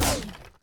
poly_shoot_bone02.wav